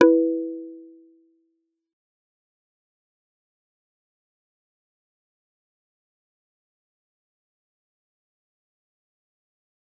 G_Kalimba-E4-f.wav